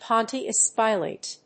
音節Pi・late 発音記号・読み方/pάɪlət/, Pontius /pάntʃəspˈɔntiəs/